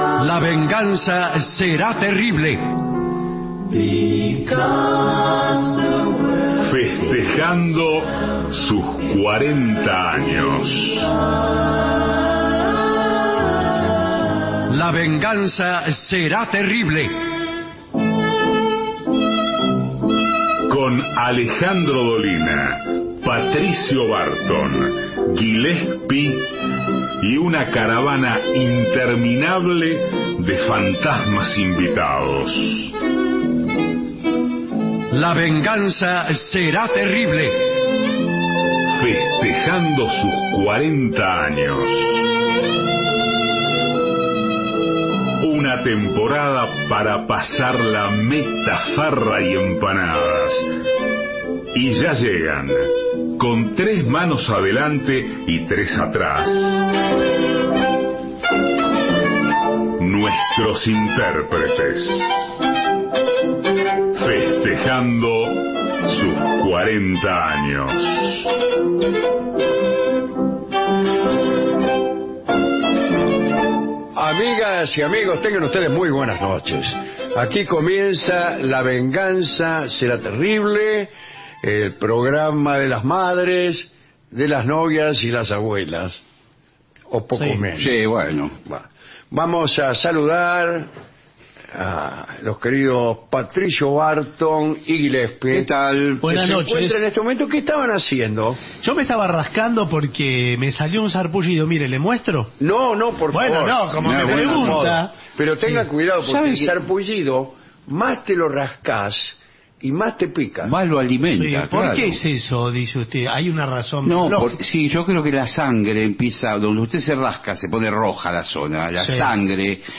Estudios AM 750